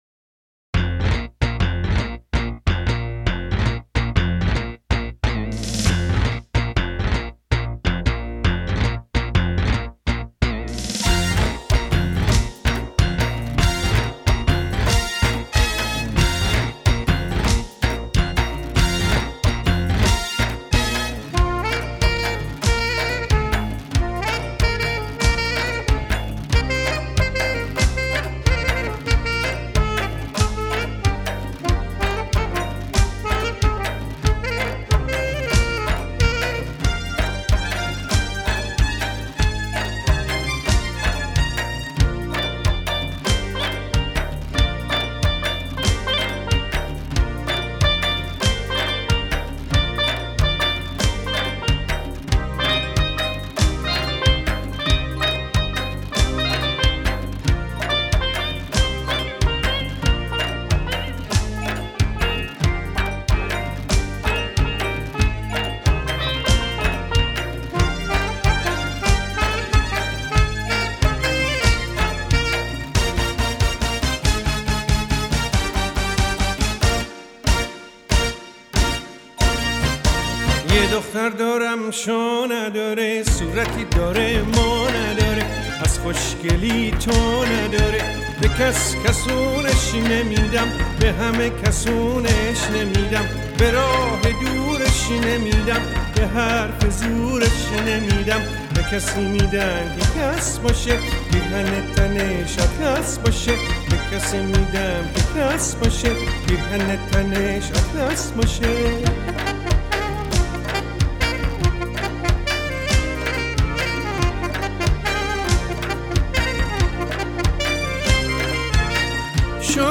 آهنگ های قدیمی شاد
متن اهنگ شاد عروسی: